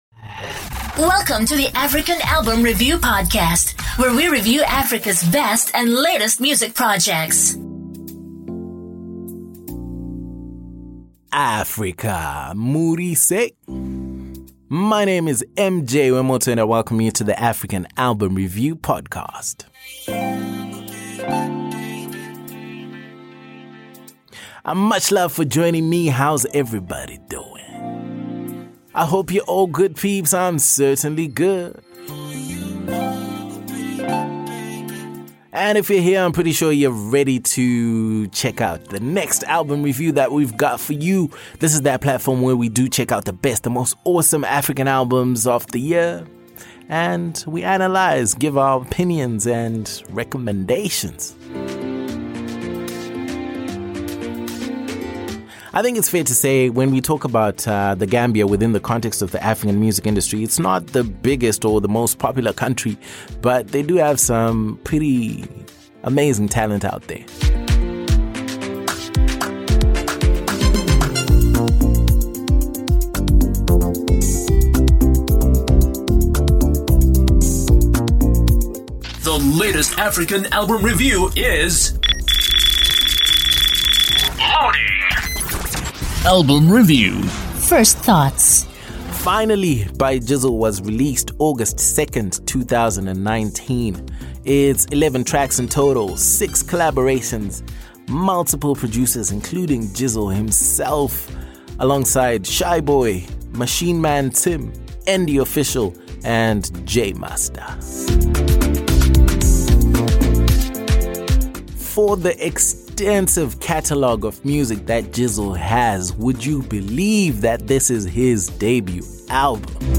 Jizzle: Finally | ALBUM REVIEW Gambia ~ African Album Review Podcast